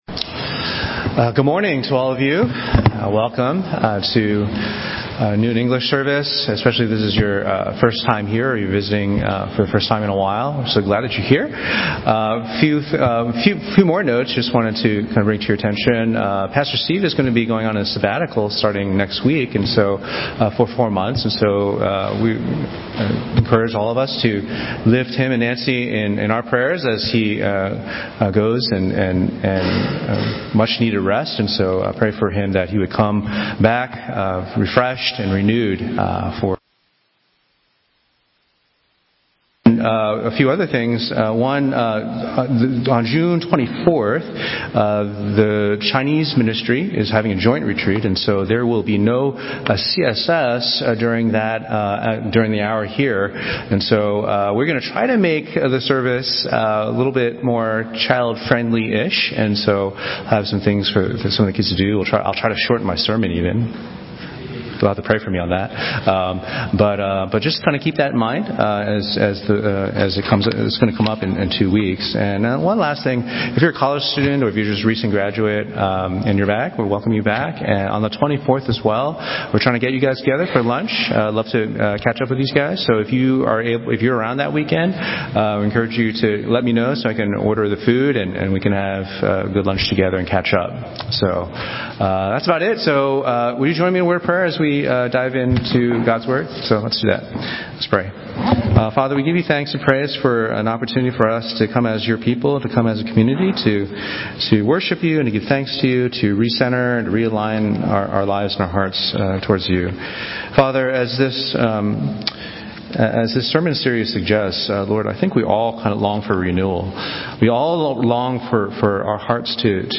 Sermons - Page 23 of 74 | Boston Chinese Evangelical Church